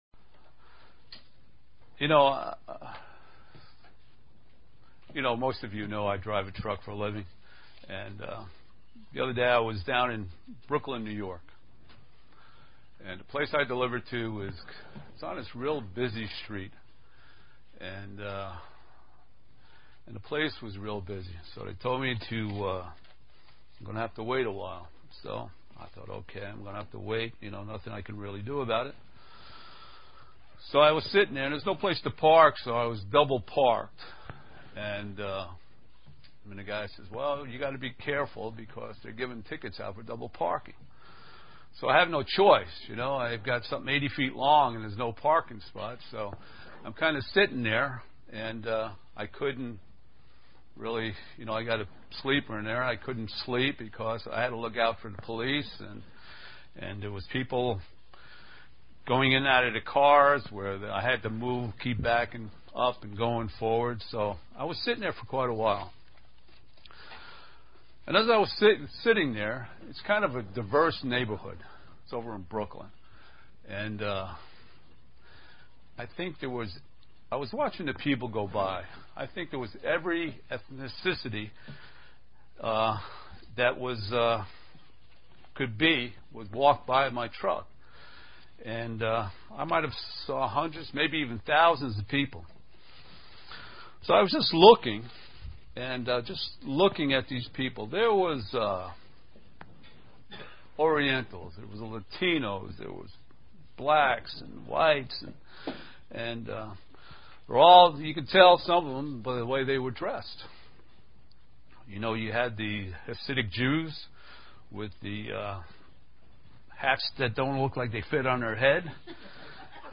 Print As we approach the Passover what does it mean to be clothed in humility UCG Sermon Studying the bible?
Given in Elmira, NY